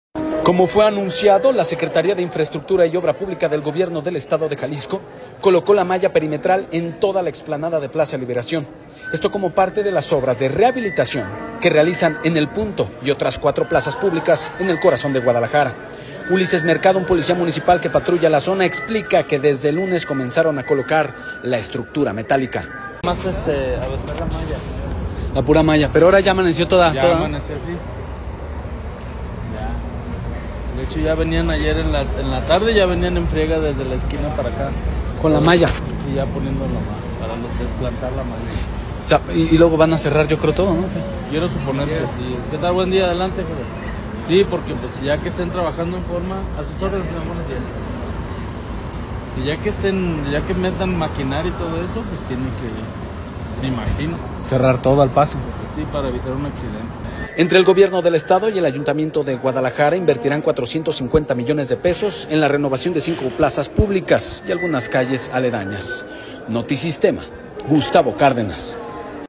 un policía municipal que patrulla la zona, explica que desde el lunes comenzaron a colocar la estructura metálica.